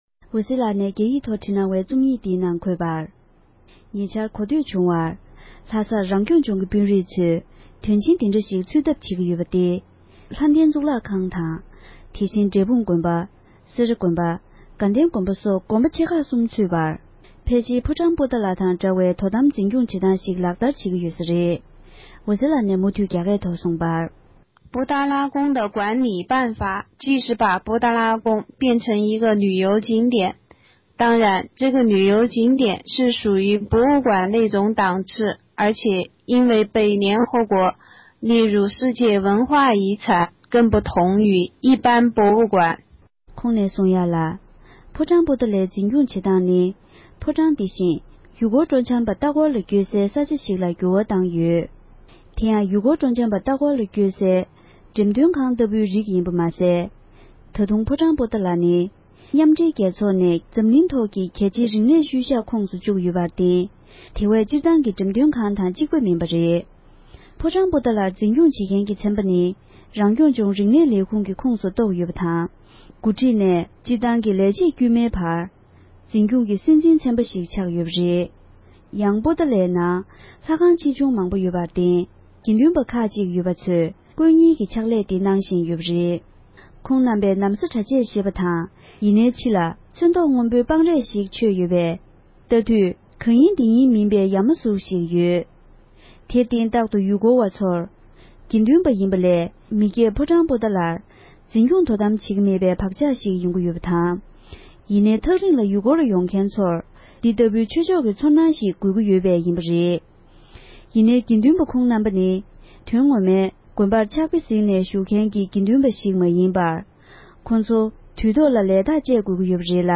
བོད་སྐད་ཐོབ་ཕབ་བསྒྱུར་གྱིས་སྙན་སྒྲོན་ཞུས་པར་གསན་རོགས་གནང༌༎